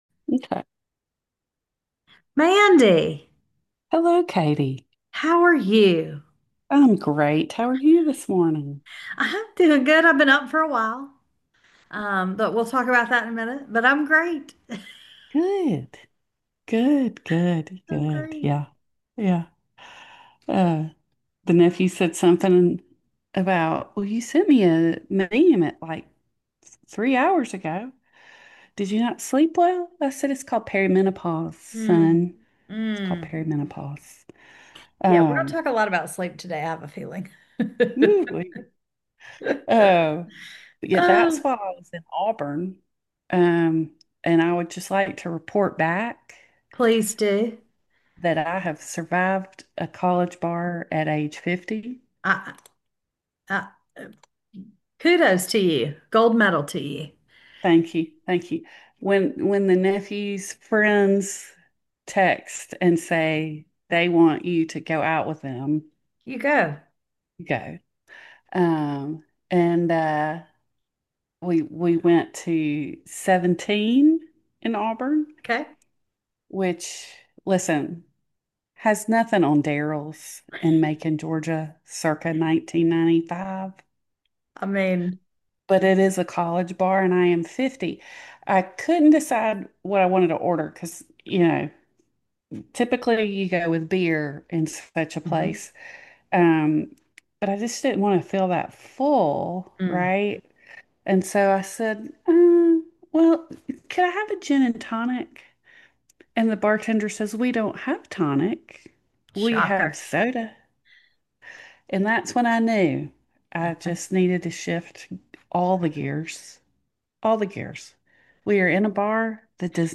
As we ponder who let all of these dogs out, you will hear barking and yawning and shushing as we try and navigate life with two dogs each.